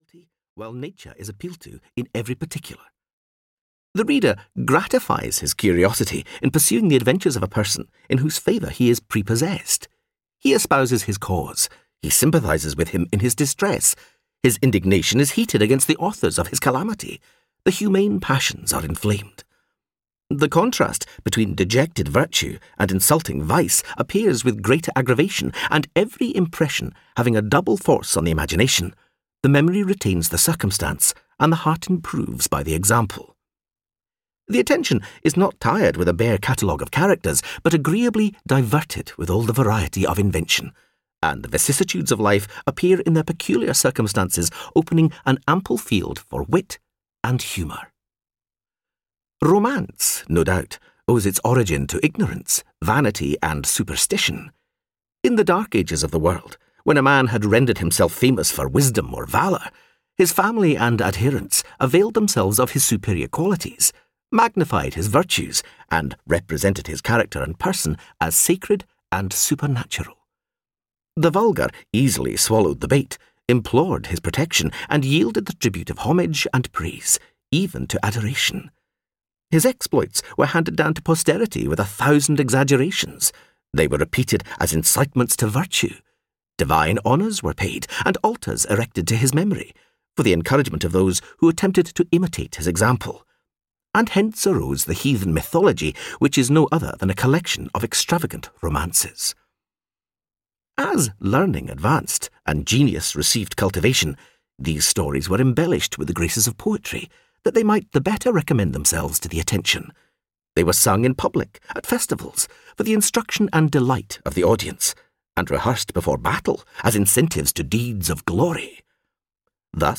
Audio knihaThe Adventures of Roderick Random (EN)
Ukázka z knihy